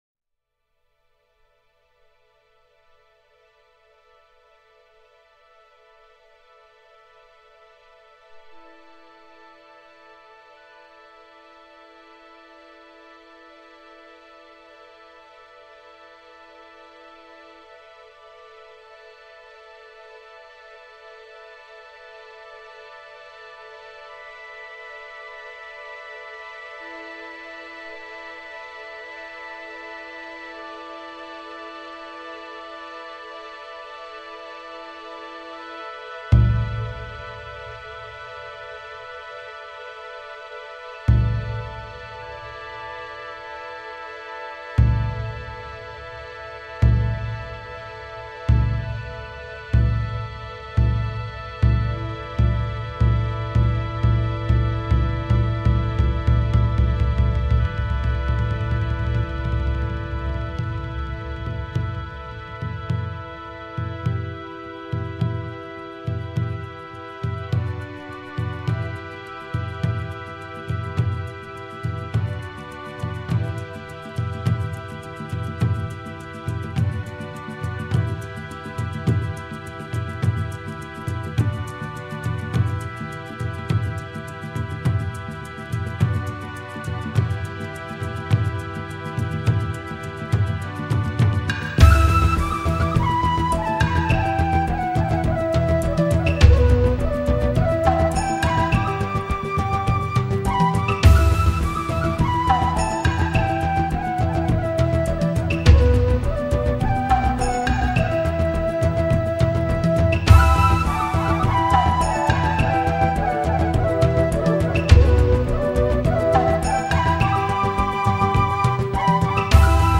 Popular Japanese music , Skor Angklung